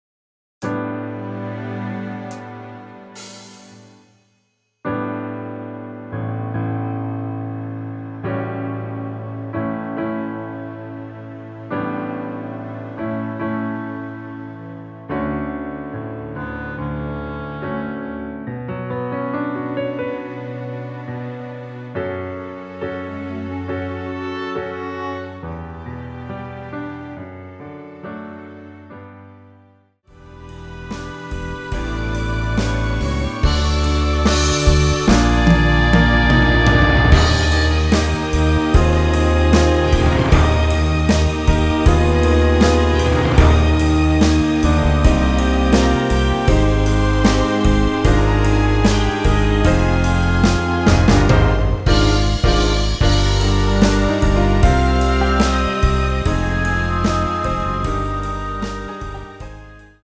앞부분30초, 뒷부분30초씩 편집해서 올려 드리고 있습니다.
중간에 음이 끈어지고 다시 나오는 이유는
뮤지컬